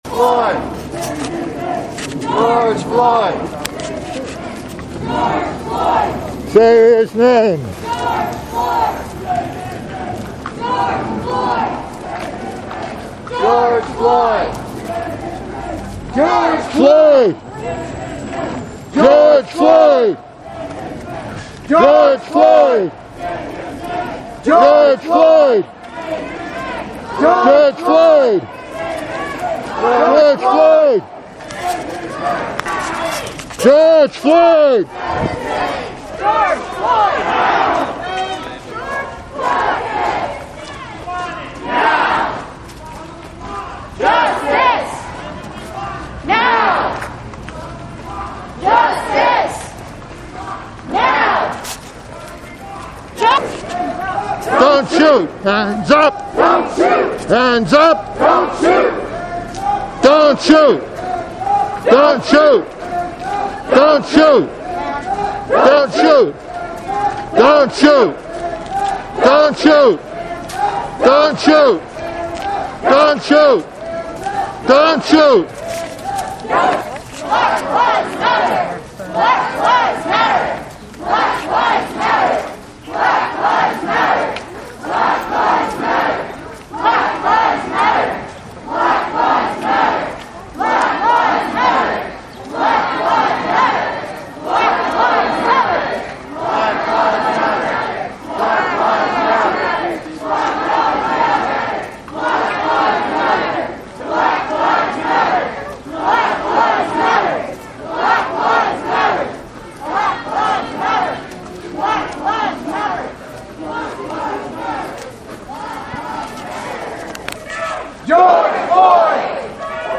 Chanting “Say His Name – George Floyd” and “Don’t Shoot! Hands Up!: they marched.
Here is four minutes of the march’s chants: